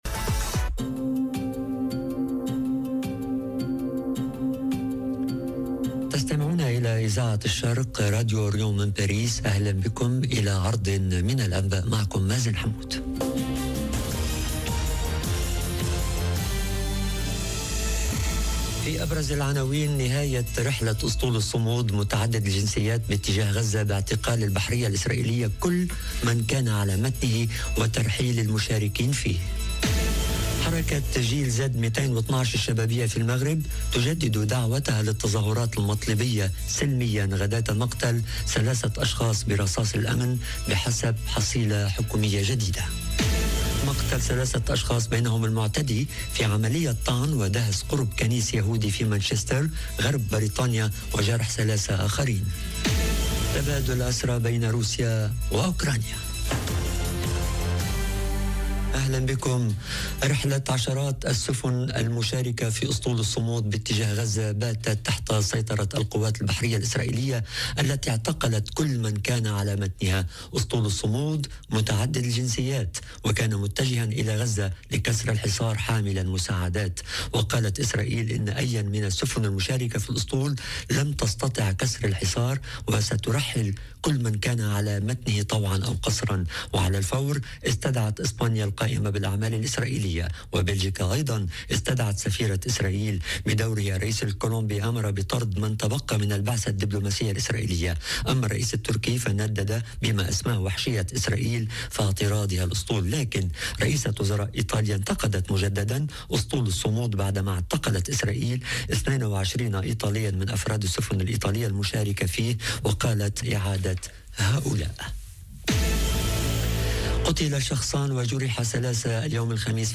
نشرة أخبار المساء: نهاية رحلة اسطول الصمود متعدد الجنسيات باتجاه غزة باعتقال البحرية الإسرائيلية كل من كان على متنه وترحيل المشاركين فيه - Radio ORIENT، إذاعة الشرق من باريس